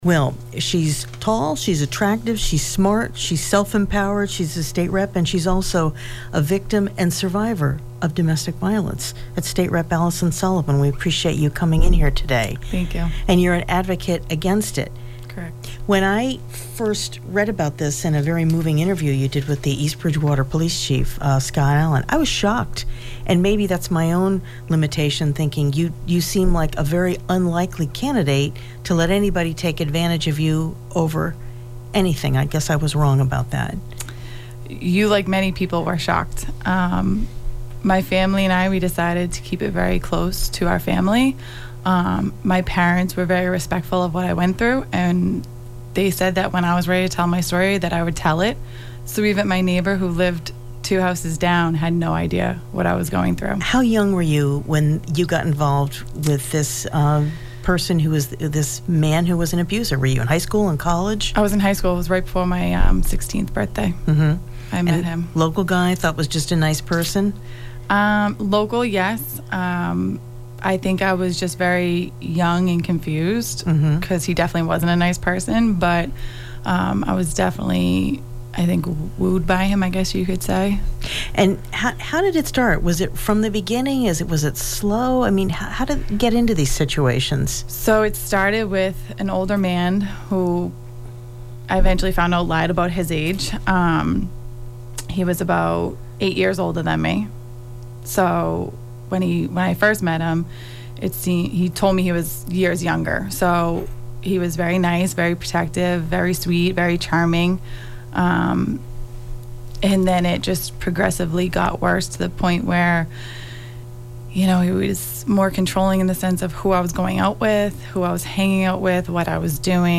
Young, smart, attractive and self-confident State Representative Alyson Sullivan is not someone that you would imagine would be the victim of domestic violence, but she was. She survived and shared her story